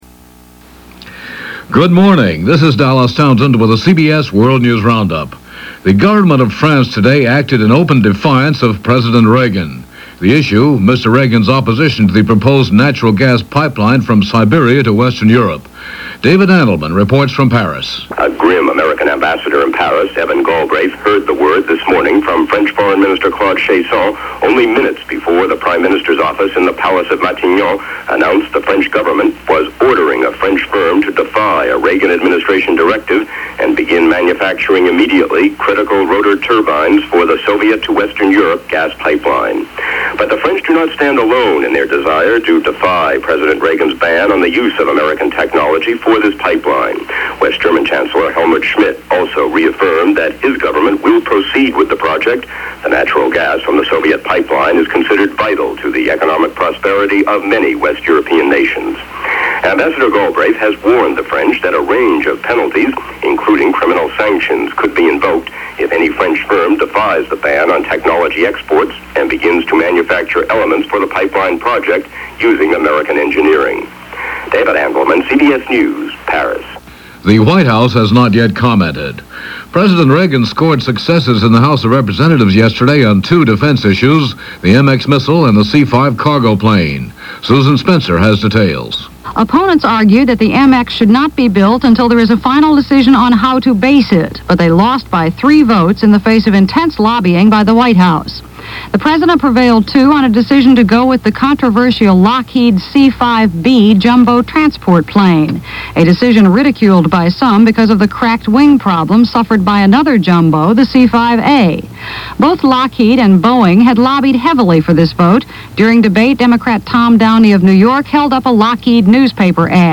And that’s a small slice of what happened, this July 22, 1982 as reported on The CBS World News Roundup.